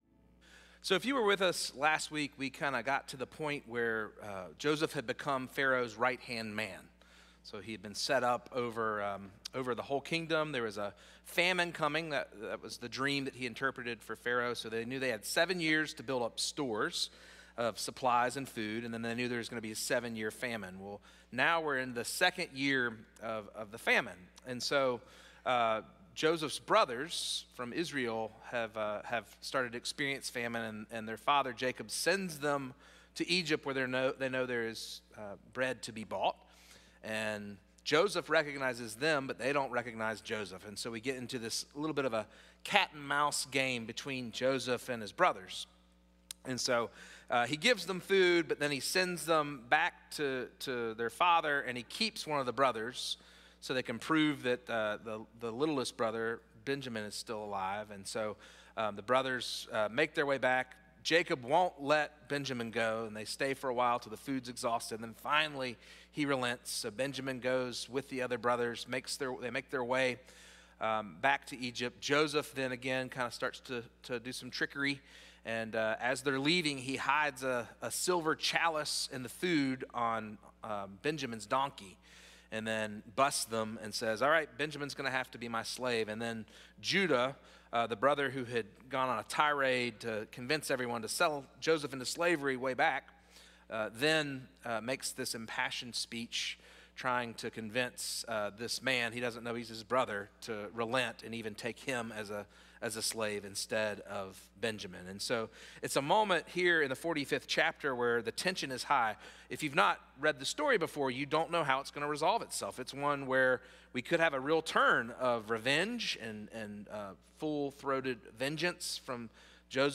First Cary UMC's First Sanctuary Sermon